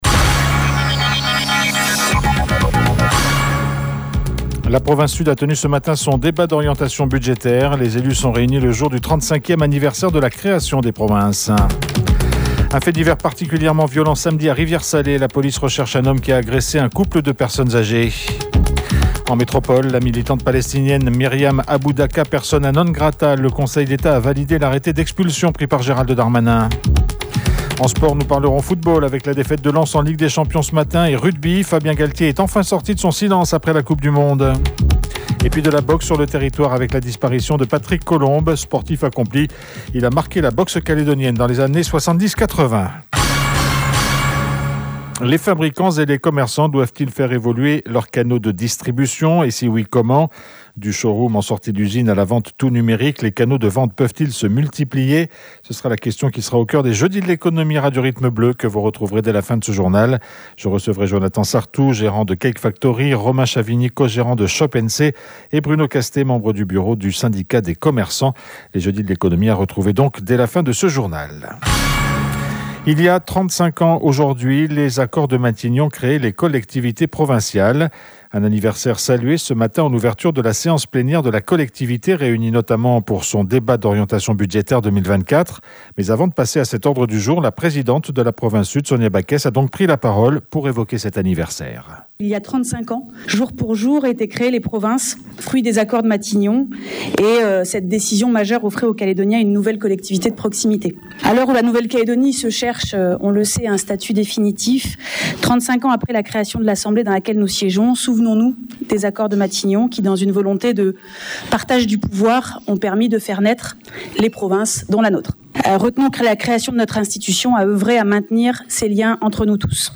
JOURNAL : INFO MAG JEUDI